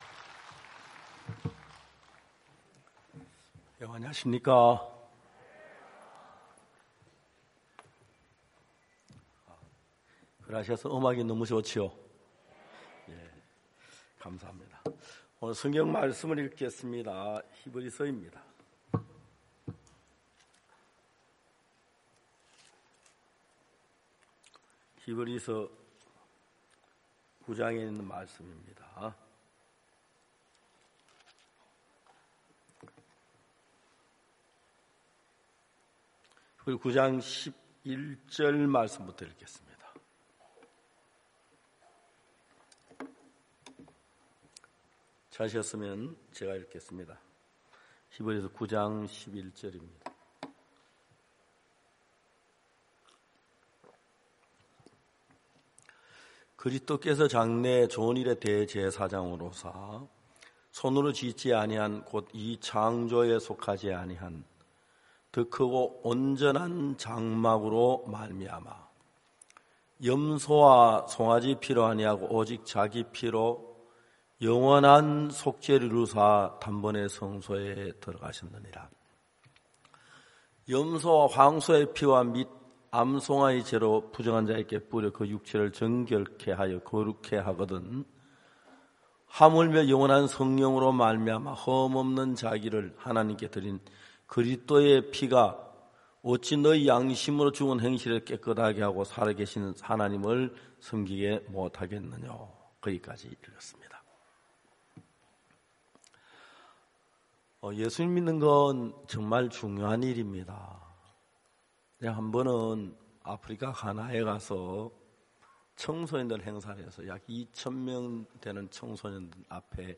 GoodNewsTV Program 2022 후반기 부산 성경세미나 #4 값없이 의롭다 하심을 얻은 자 되었느니라